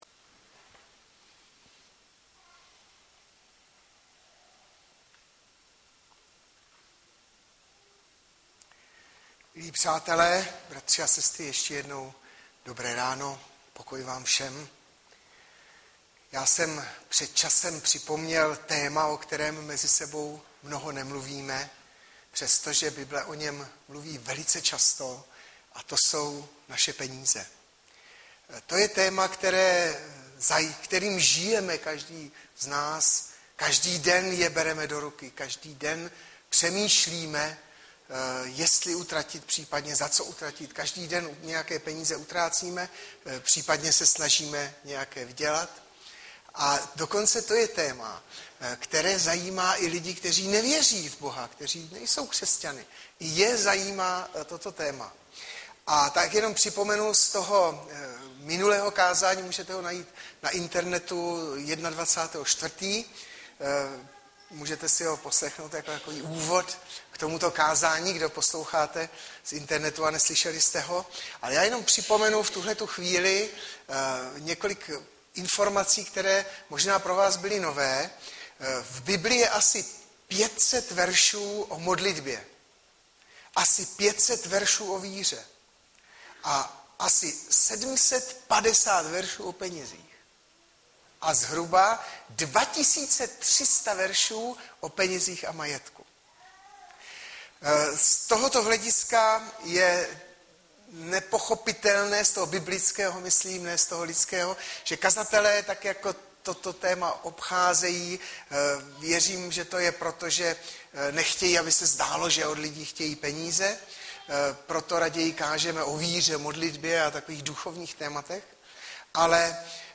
Webové stránky Sboru Bratrské jednoty v Litoměřicích.
Hlavní nabídka Kázání Chvály Kalendář Knihovna Kontakt Pro přihlášené O nás Partneři Zpravodaj Přihlásit se Zavřít Jméno Heslo Pamatuj si mě  02.06.2013 - MÁ BÝT KŘESŤAN BOHATÝ?